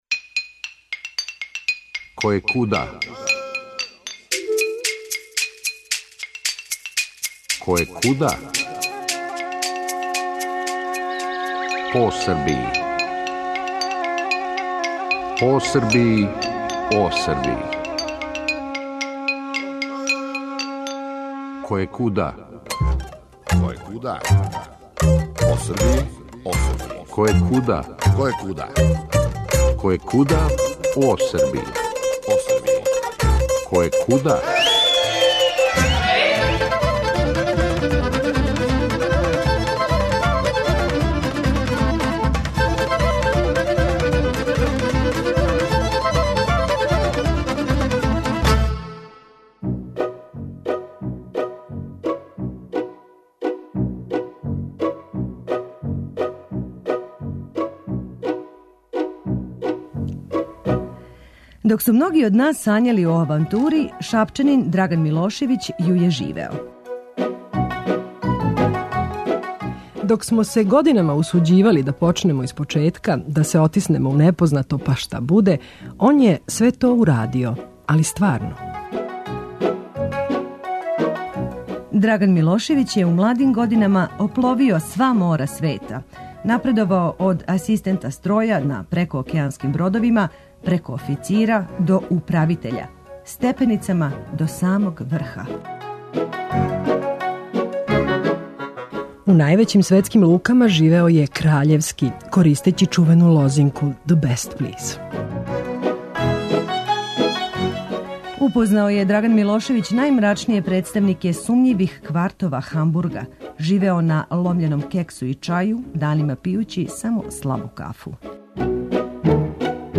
Можемо рећи да вам нудимо радијски филм у два дела, макар колико то чудно звучало.